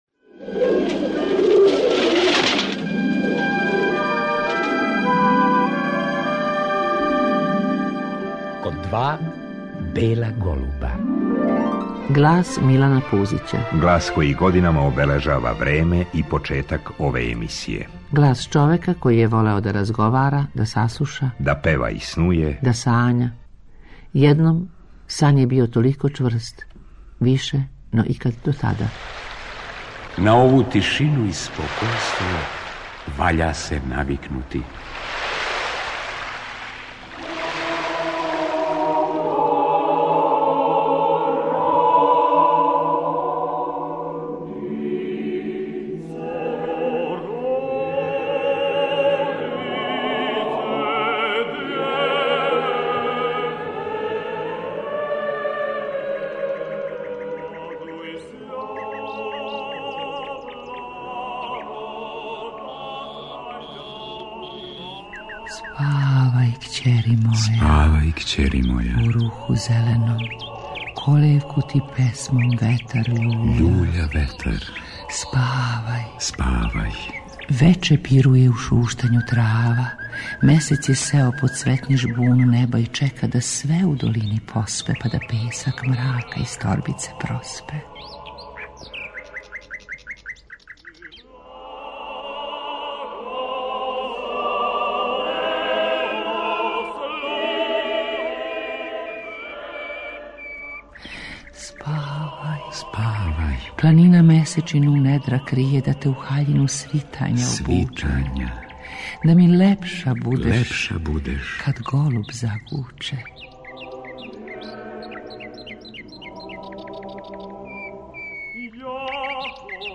Глас Милана Пузића чује се на самом почетку најаве наше емисије. Ова емисија представља спомен на драгог нам глумца, снимљена на вест о његовом одласку те 1994. године.